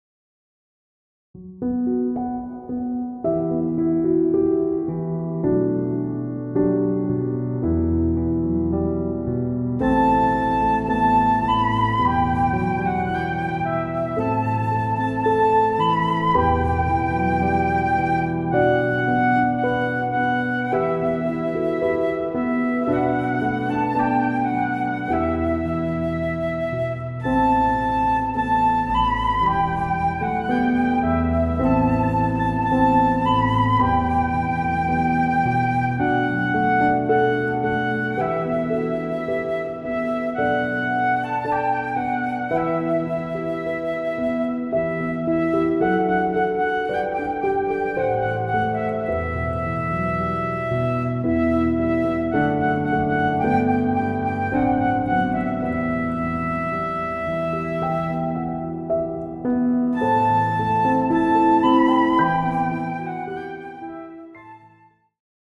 InstrumentalCOUPLET/REFRAIN